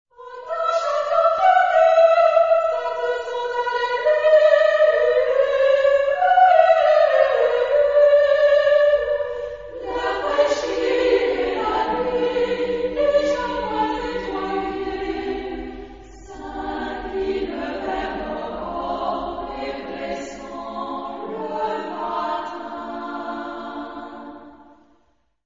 Genre-Stil-Form: zeitgenössisch ; Gedicht ; weltlich
Charakter des Stückes: poetisch
Tonart(en): C-Dur
Lokalisierung : 20ème Profane Acappella